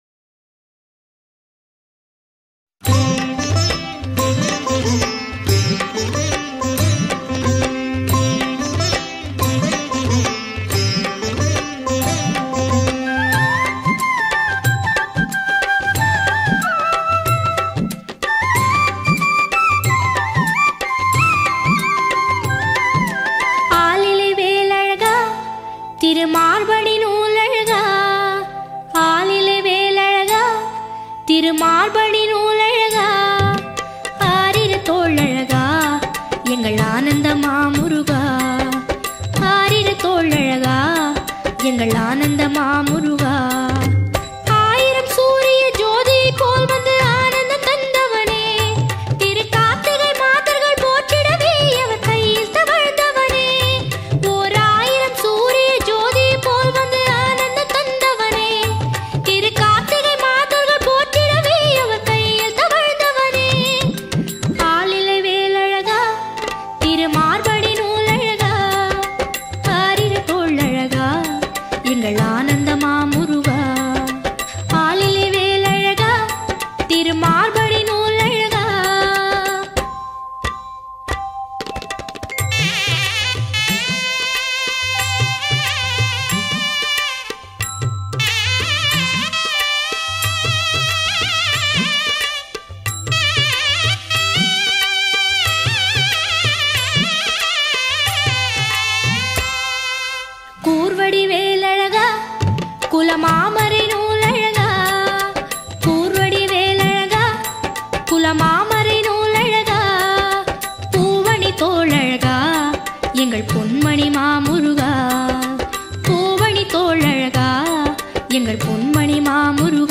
Devotional Album